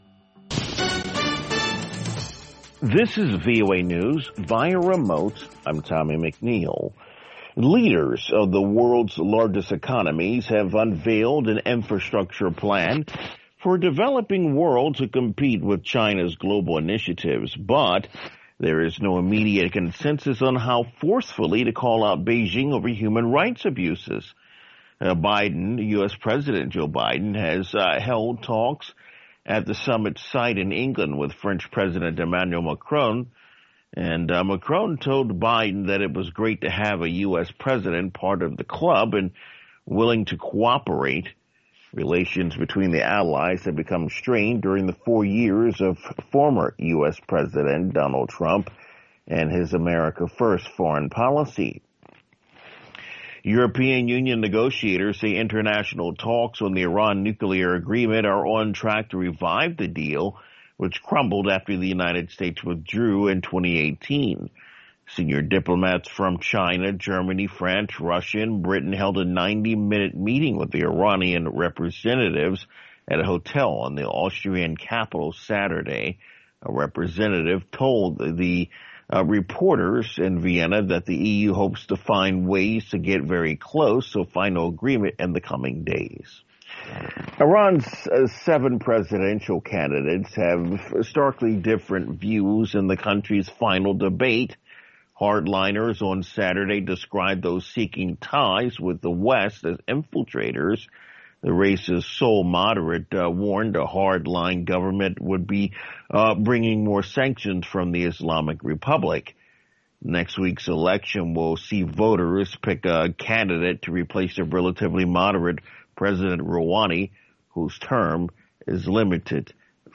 VOA Newscasts (2 Minute)